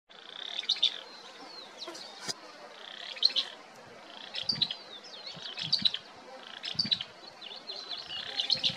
Barullero (Euscarthmus meloryphus)
Nombre en inglés: Fulvous-crowned Scrub Tyrant
Fase de la vida: Adulto
Localidad o área protegida: Reserva Natural Estricta Quebrada de las Higueritas
Condición: Silvestre
Certeza: Vocalización Grabada